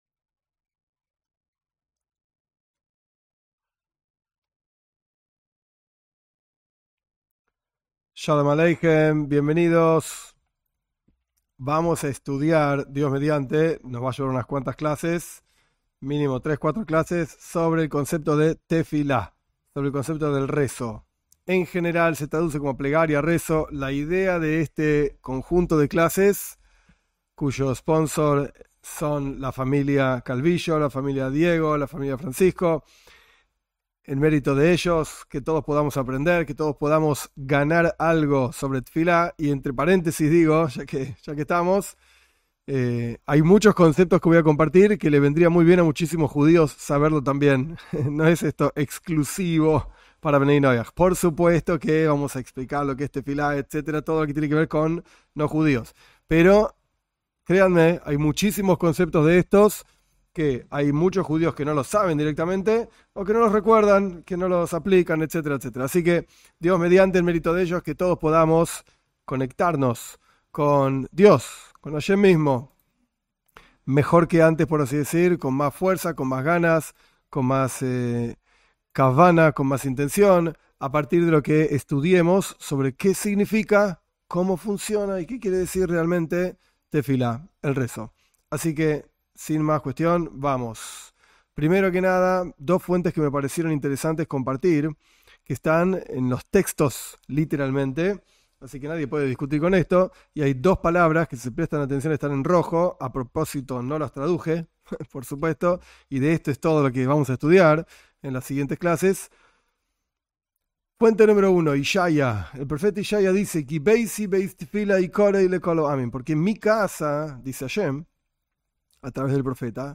En estas clases se analiza con detenimiento la plegaria para no Judíos según el mensaje del judaísmo.